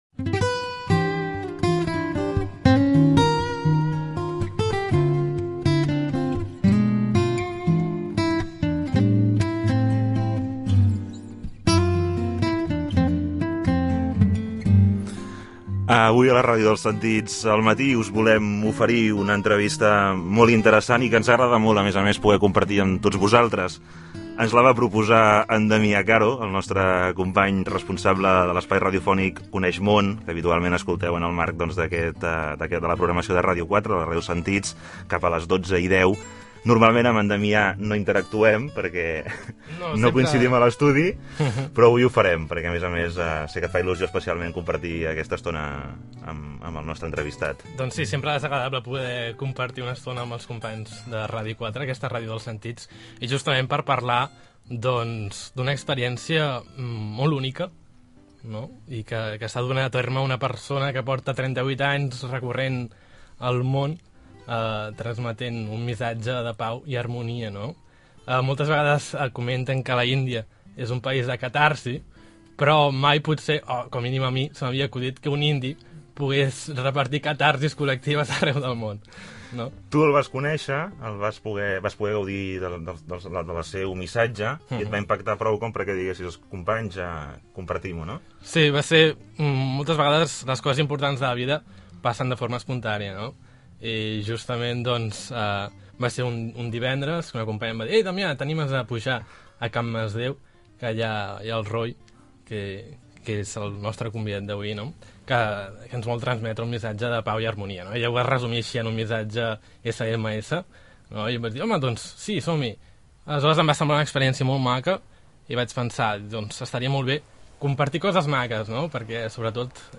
ENTREVISTA
Ha estado con nosotros en Radio 4.